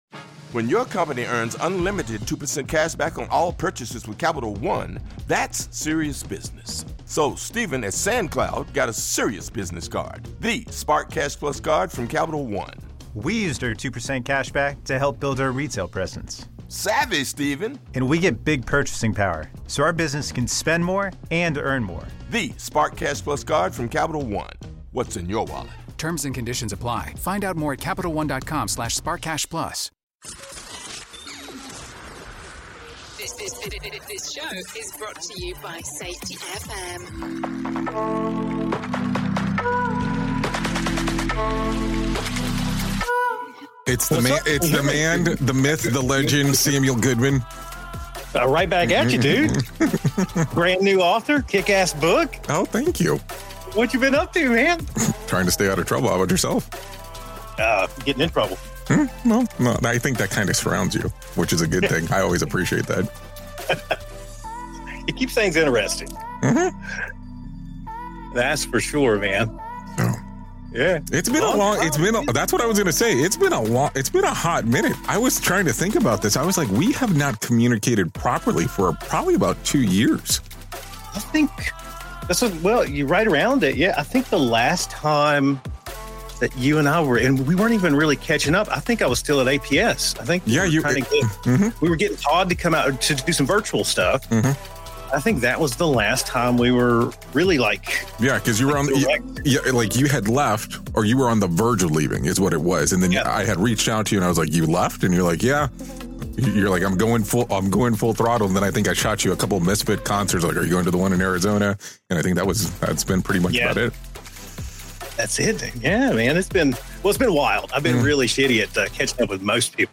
EP 738 -The Signal Between Us: A Conversation